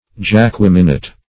Search Result for " jacqueminot" : The Collaborative International Dictionary of English v.0.48: Jacqueminot \Jacque"mi*not\, n. A half-hardy, deep crimson rose of the remontant class; -- so named after General Jacqueminot, of France.